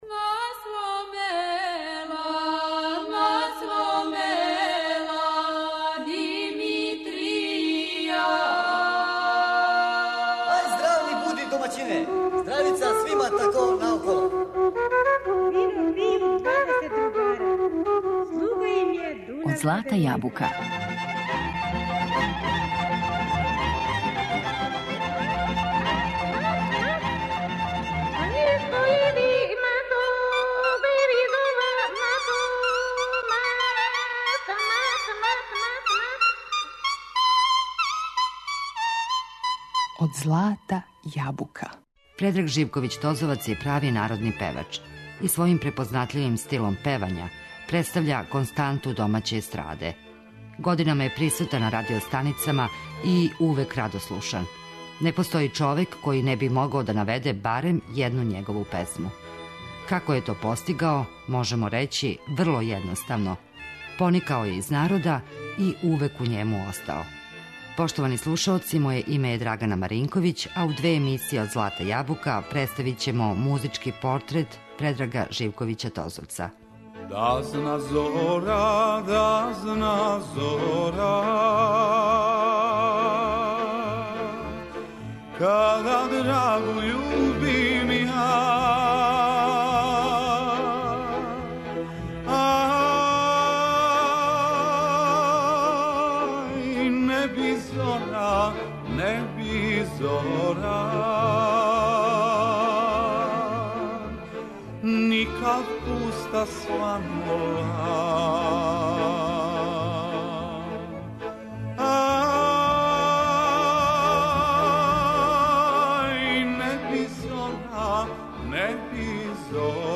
Тозовац је прави народни певач и својим препознатљивим стилом певања стекао је велику популарност.